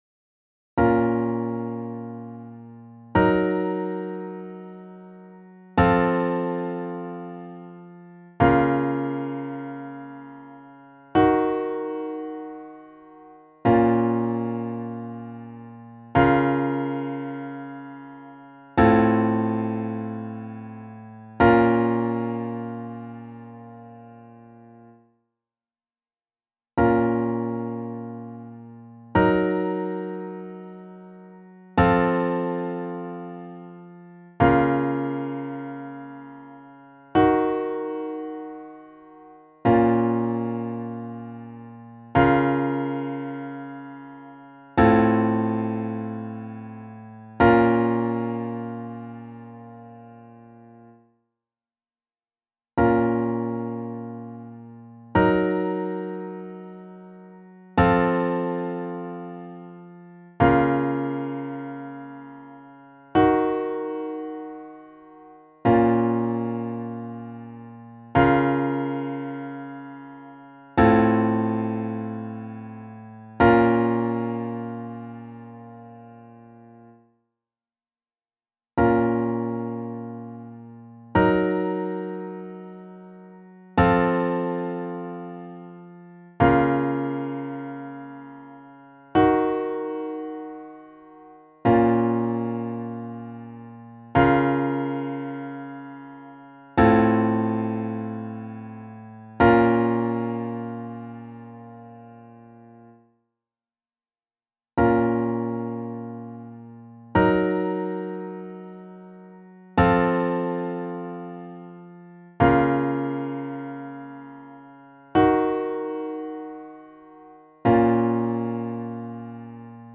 Διαδοχή Συγχορδιών. 1Ε Κύριες Βαθμίδες Ελάσσονες